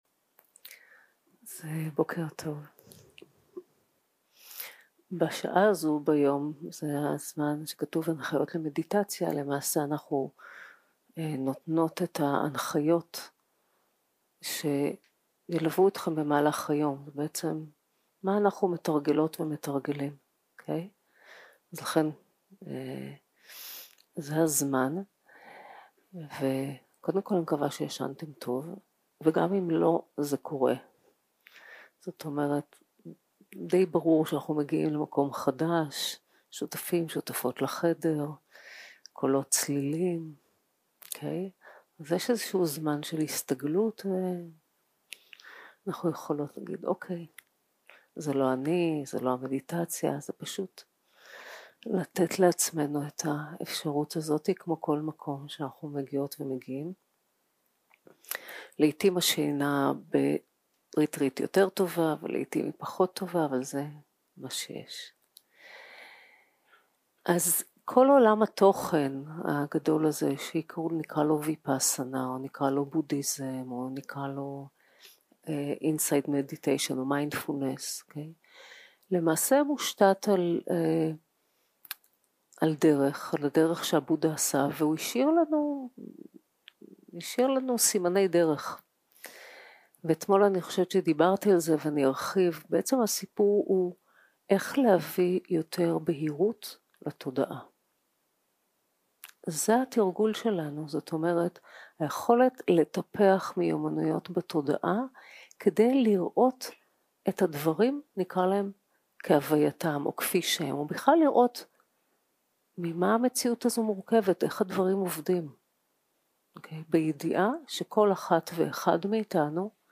יום 2 - הקלטה 2 - בוקר - הנחיות למדיטציה - ביסוס תשומת לב לגוף ונשימה 0:00 0:00 סוג ההקלטה: סוג ההקלטה: שיחת הנחיות למדיטציה שפת ההקלטה: שפת ההקלטה: עברית